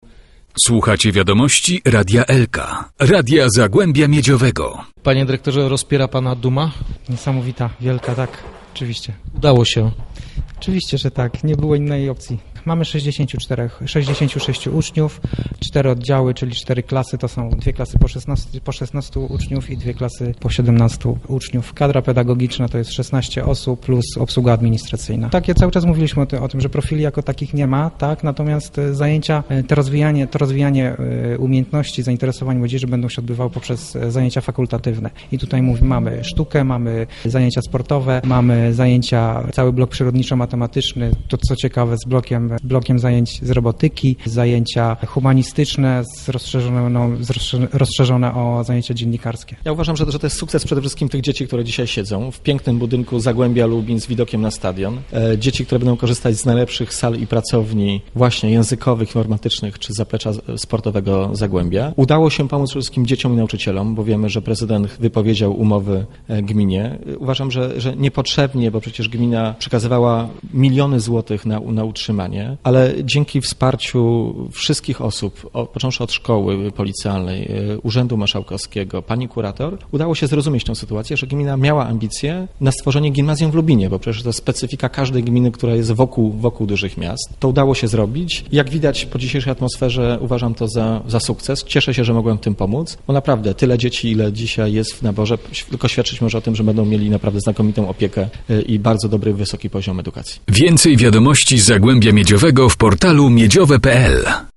europoseł PO, Piotr Borys: